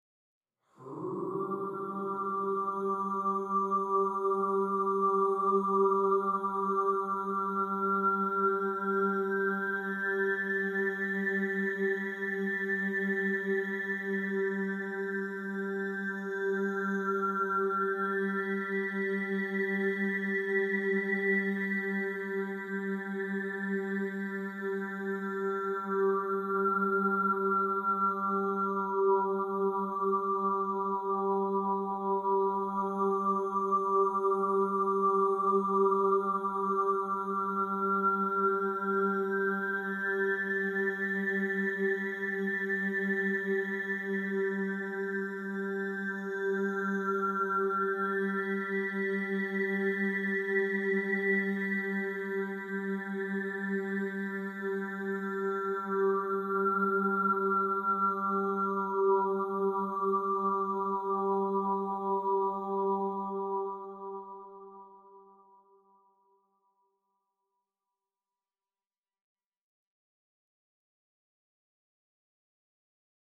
Part 3 is the solo track of the overtone singing